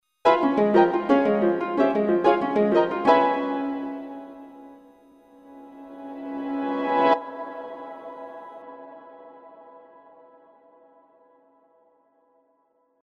Harp.mp3